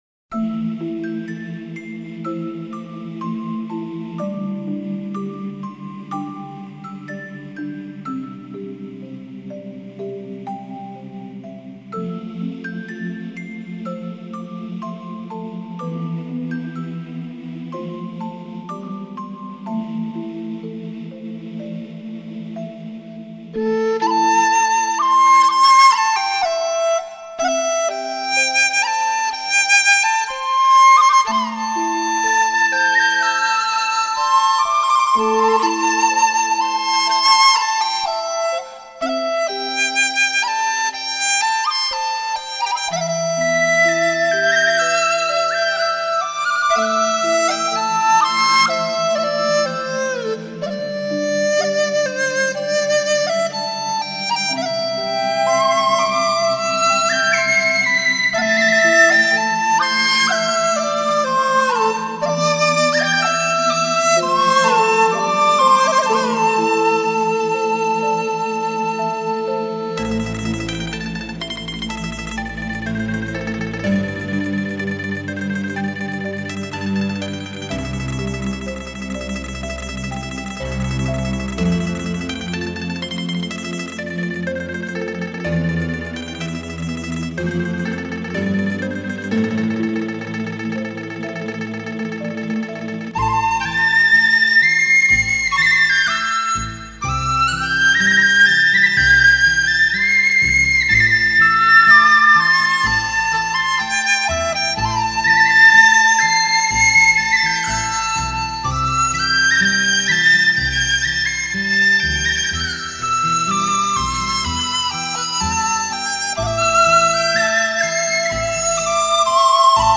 以曲笛、梆笛、新笛、口笛、低音笛、木笛为主，辅以领奏、轮奏、二重奏、三重奏加之背景技巧融为一体的全新演绎手法。
中国竹笛就是好听！太喜欢啦！